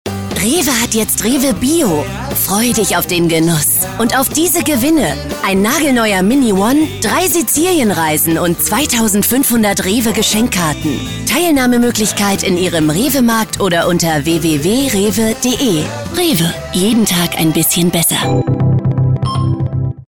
Sychronausschnitt Jessica Alba